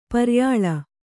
♪ paryāḷa